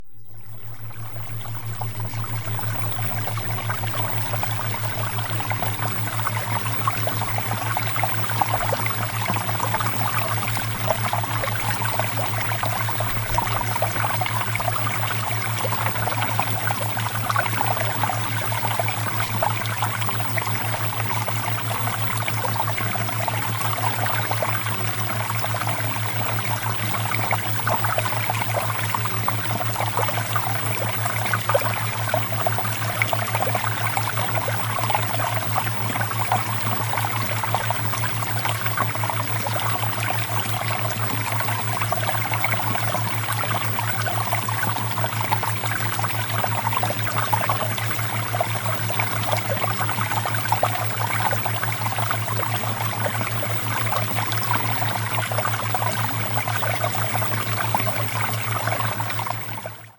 SEXUAL-MAGNETISM-Water-Theta-Waves-Sample.mp3